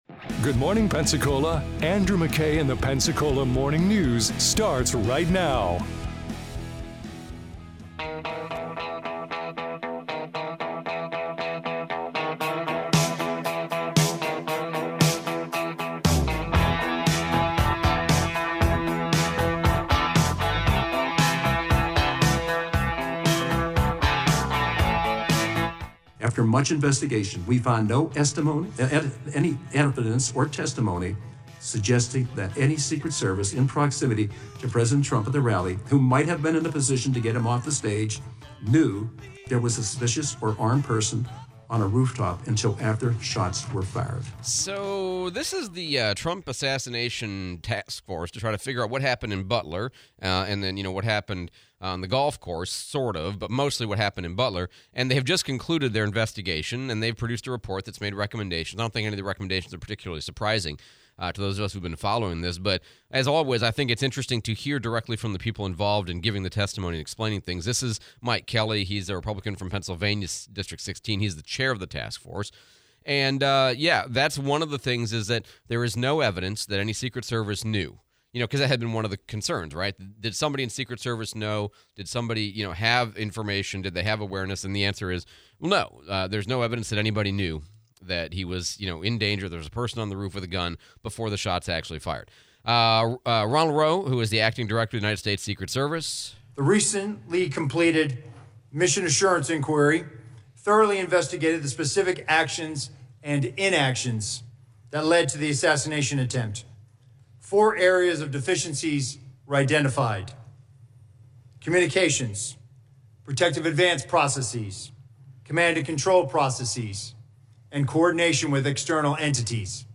Secret Service report, DC Reeves Interview